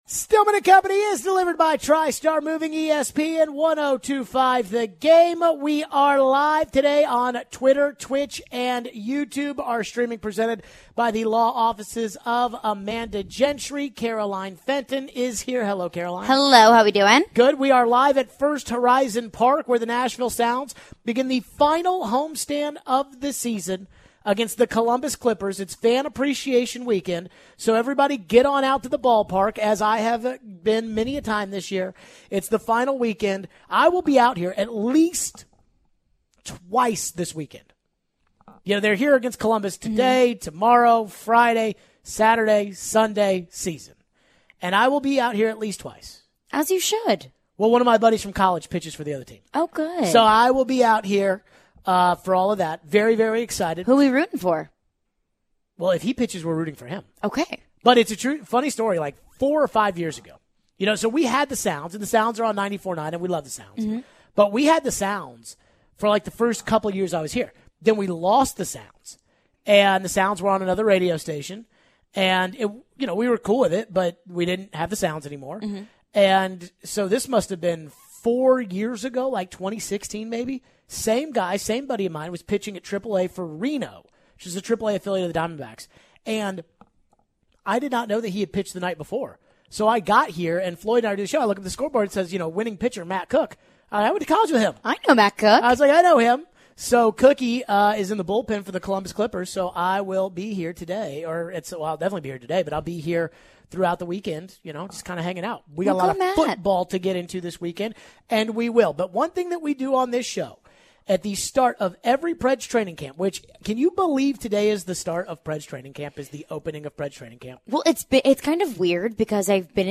We take your phone calls on the Preds expectations and the roster. We transition to some Titans talk with our weekly visit with forever Titans WR Chris Sanders.